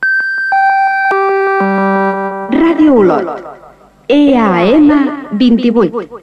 Indicatiu com Ràdio Olot EAM 28